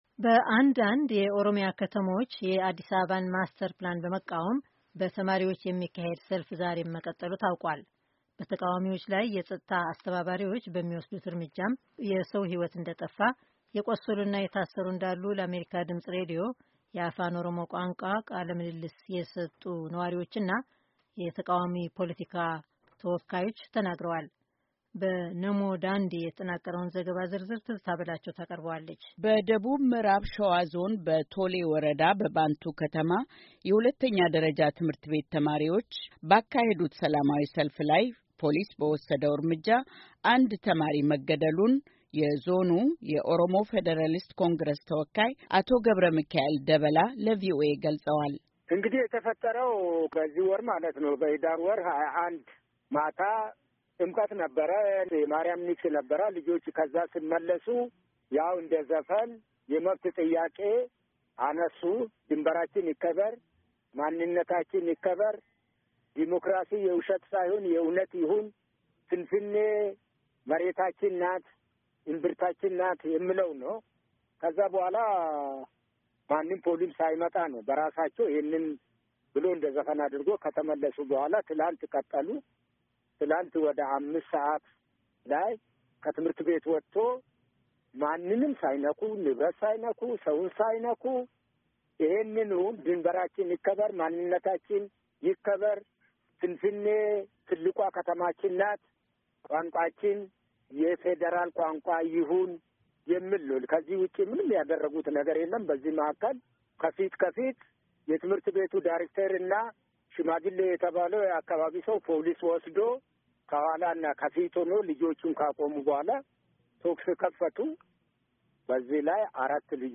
በተቃዋሚዎች ላይ የጸጥታ አስከባሪዎች በሚወስዱት እርምጃም የሰዉ ሕይወት እንደጠፋ፥ የቁሰሉና የታሰራ እንዳሉ ለአሜሪካ ድምጽ ራዲዮ የአፋን ኦሮሞ ቋንቋ ቃለ ምልልስ የሰጡ ነዋሪዎችና የተቃዋሚ ፓለቲካ ተወካዮች ተናግረዋል።